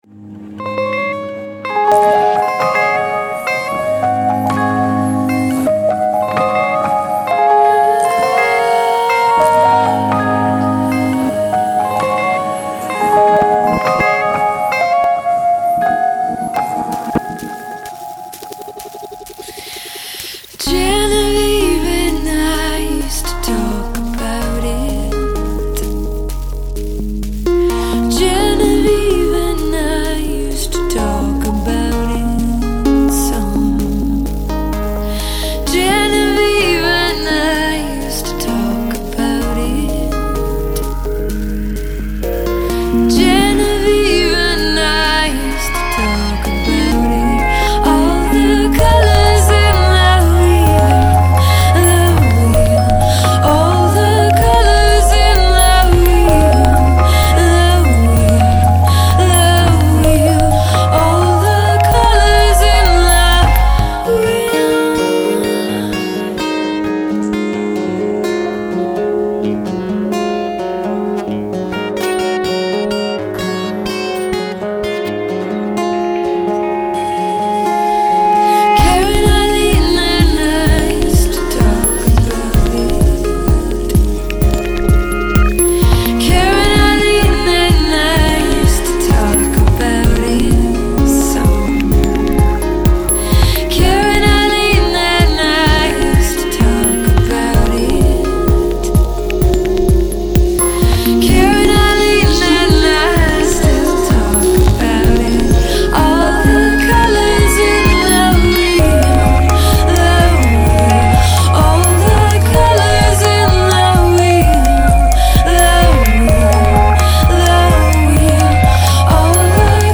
This is probably our most experimental album.